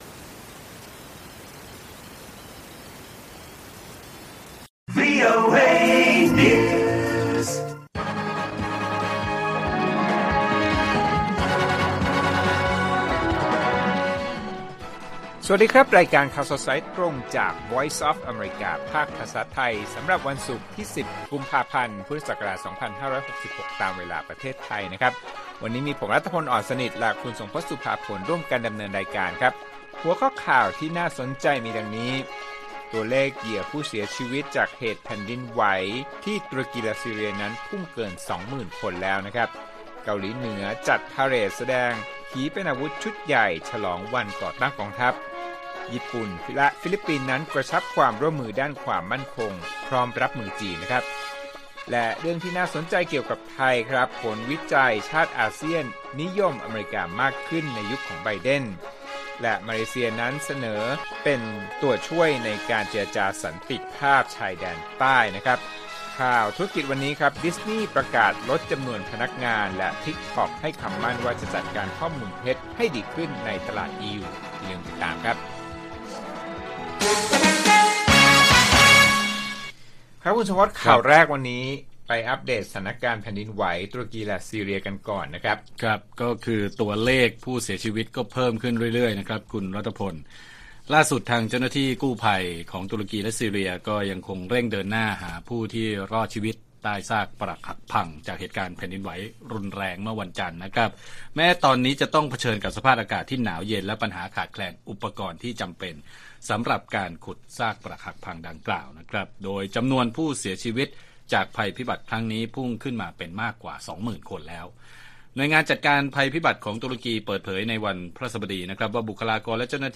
ข่าวสดสายตรงจากวีโอเอไทย ศุกร์ ที่ 10 ก.พ. 66